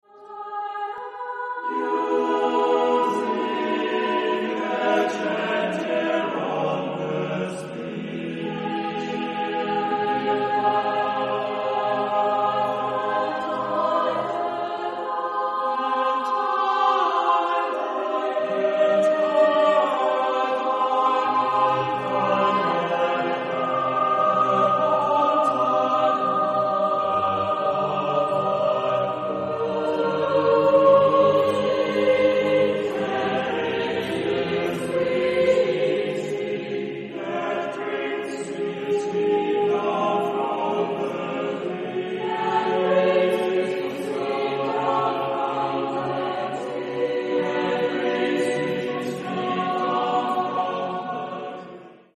Genre-Stil-Form: Chorwerk ; weltlich
Chorgattung: SSAATTBB  (8 gemischter Chor Stimmen )